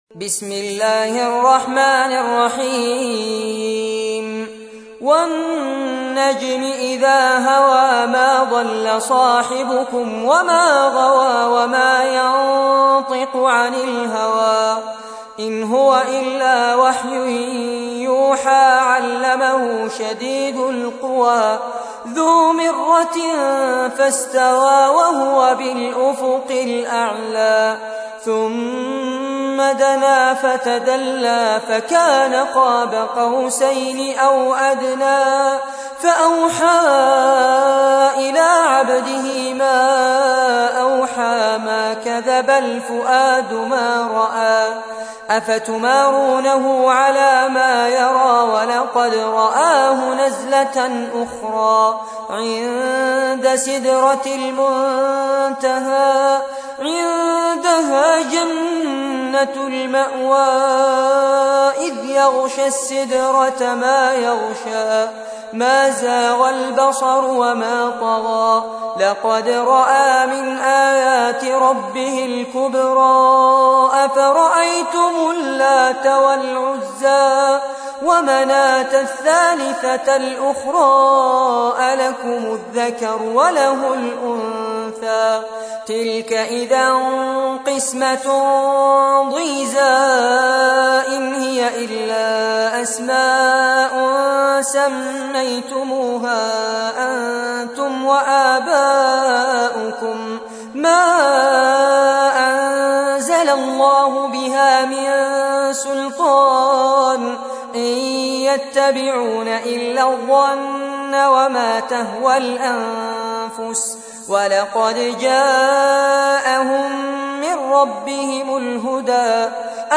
تحميل : 53. سورة النجم / القارئ فارس عباد / القرآن الكريم / موقع يا حسين